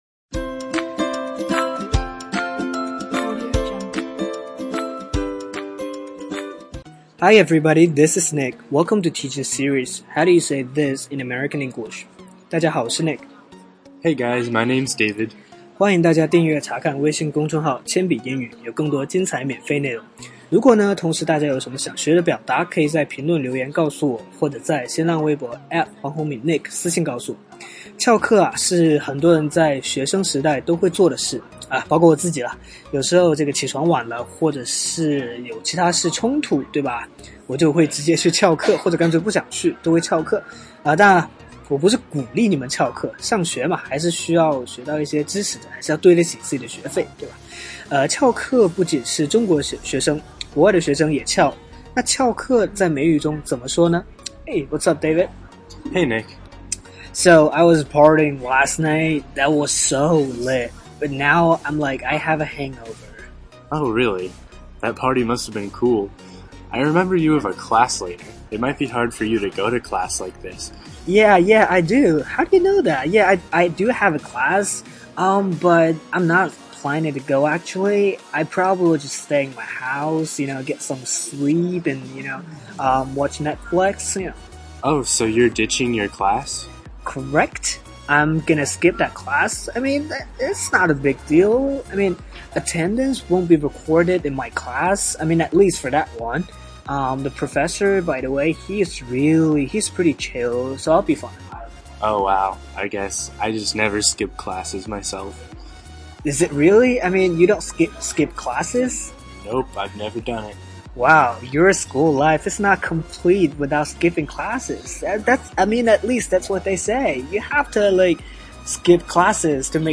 音频中汉语及英语交叉出现，适合不同层次的英语学习者。